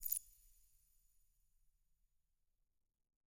Minecraft Version Minecraft Version latest Latest Release | Latest Snapshot latest / assets / minecraft / sounds / block / eyeblossom / eyeblossom_open1.ogg Compare With Compare With Latest Release | Latest Snapshot
eyeblossom_open1.ogg